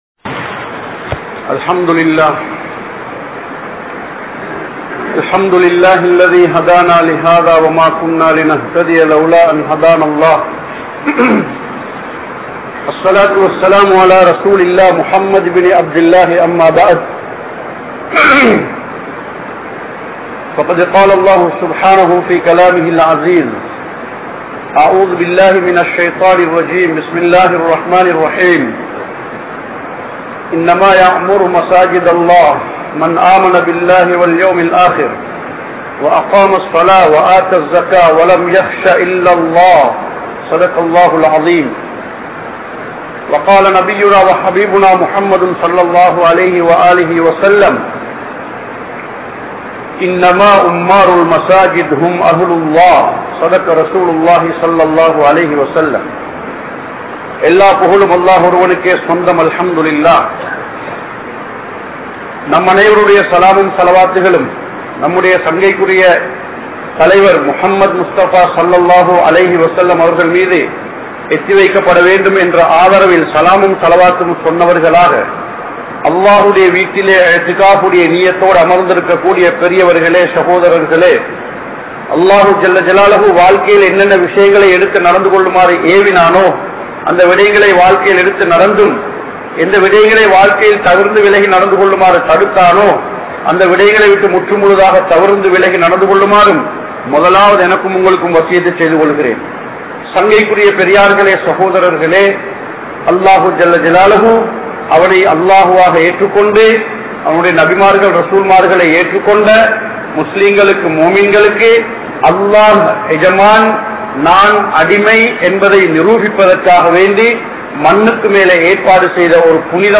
Masjidhin Sirappuhal (மஸ்ஜிதின் சிறப்புகள்) | Audio Bayans | All Ceylon Muslim Youth Community | Addalaichenai